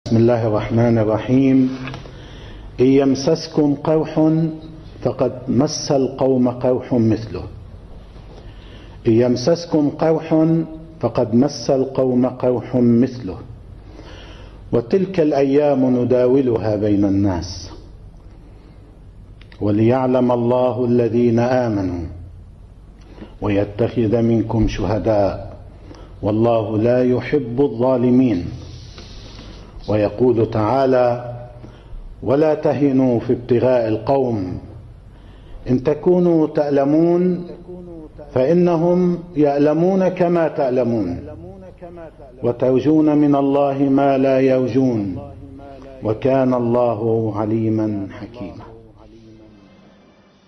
مقطع صوتي وفيديو من آخر خطبة لسيد المقاومة السيد حسن نصر الله..